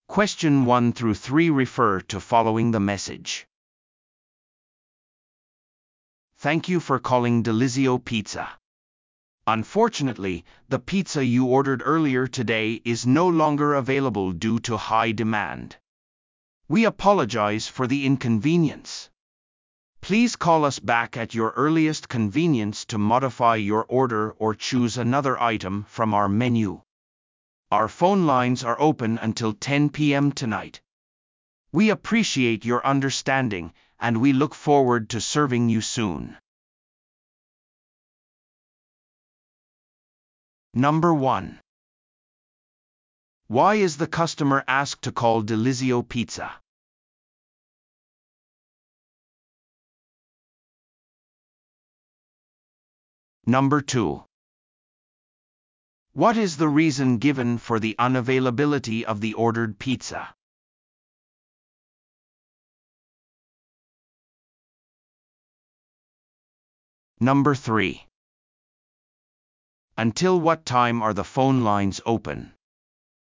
PART４は一人語りの英語音声が流れ、それを聞き取り問題用紙に書かれている設問に回答する形式のリスニング問題。